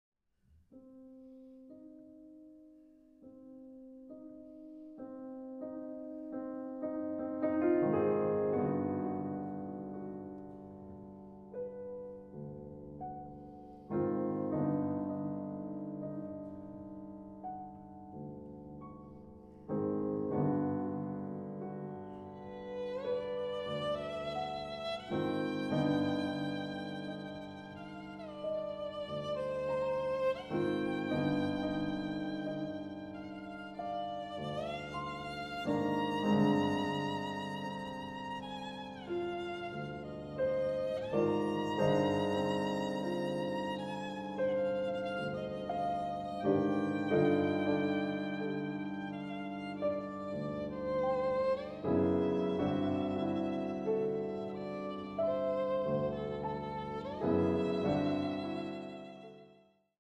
Allegro appassionato